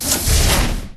doorsclosed.wav